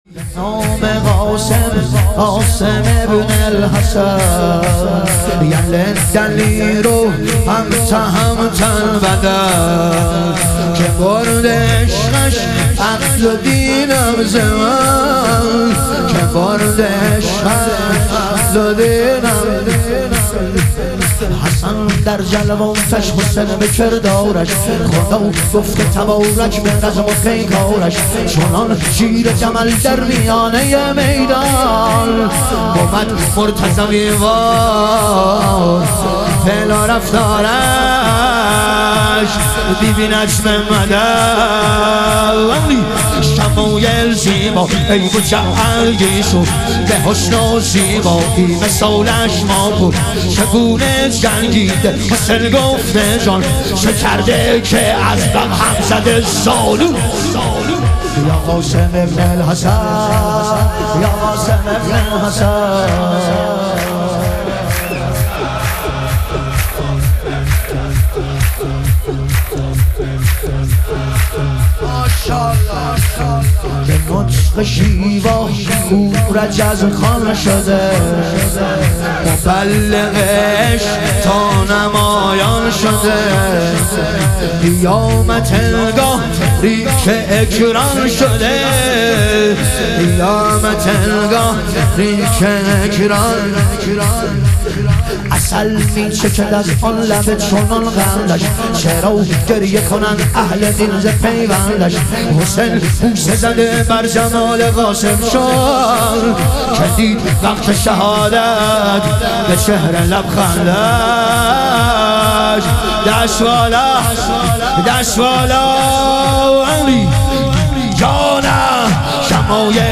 ظهور وجود مقدس حضرت قاسم علیه السلام - شور